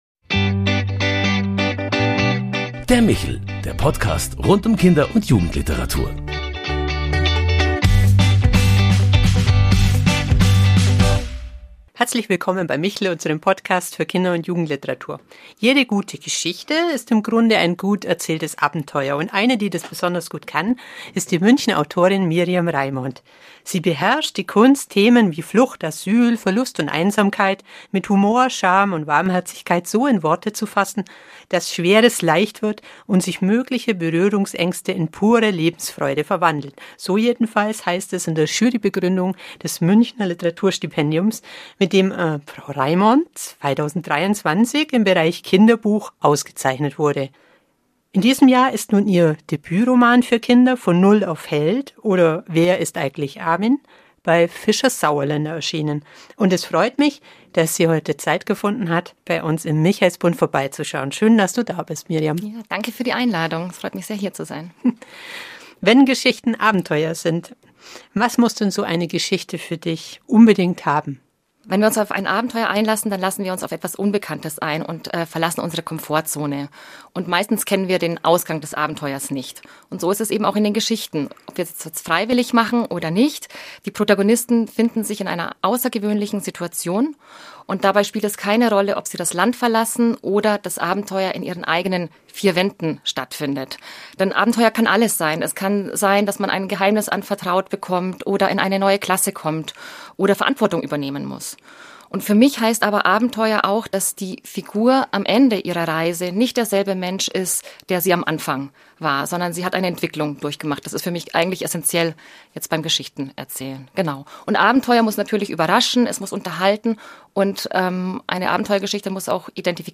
Ein Gespräch über Mut, Perspektivwechsel und die Kraft von Geschichten, die Herzen öffnen.